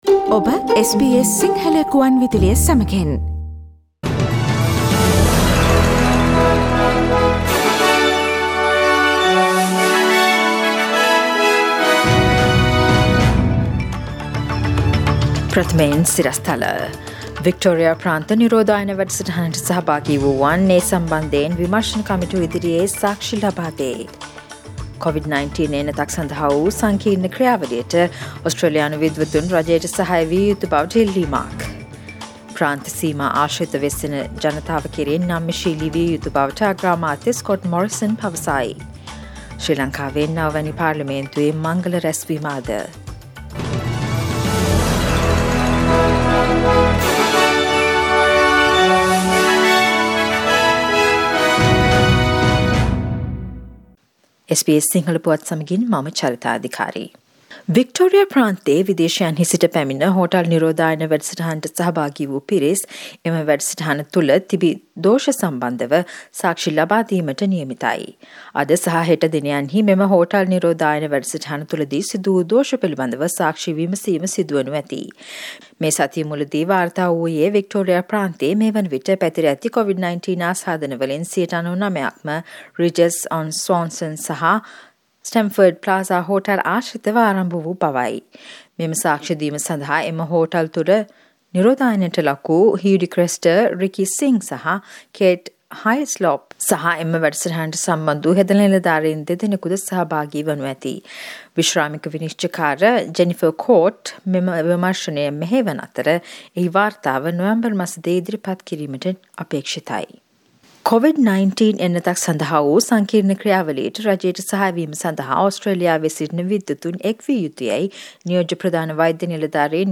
Daily News bulletin of SBS Sinhala Service: Thursday 20 August 2020